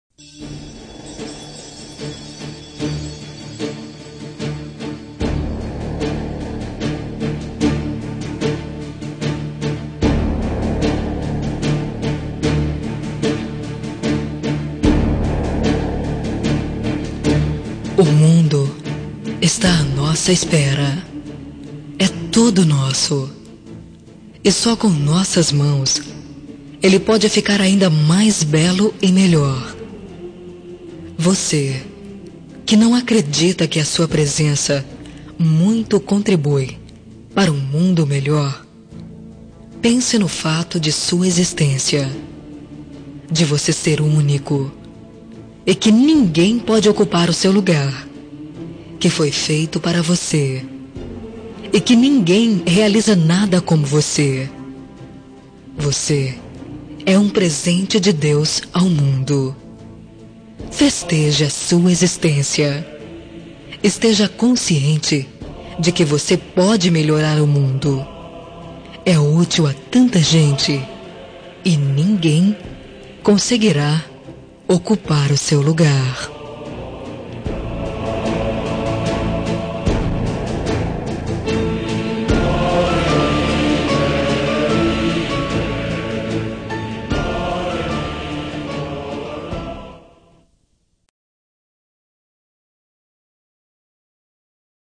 Telemensagem de Otimismo – Voz Feminina – Cód: 4460